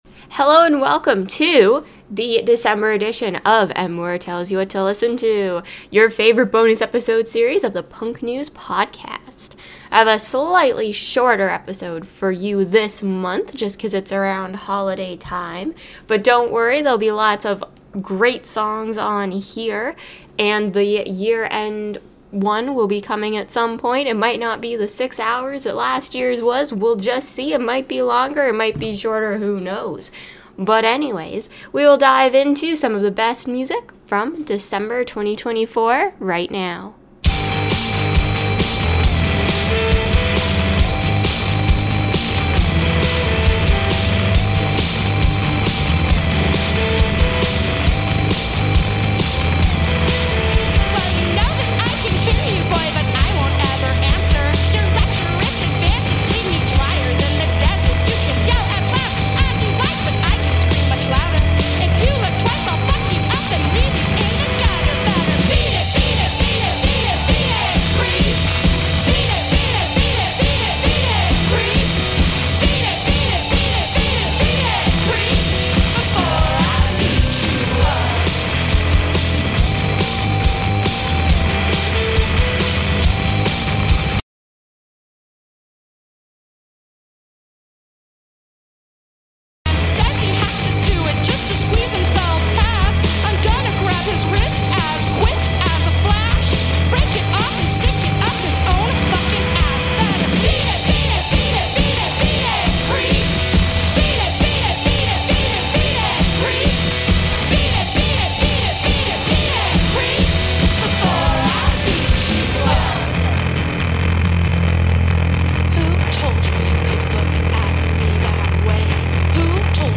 plays the hottest jamz from December.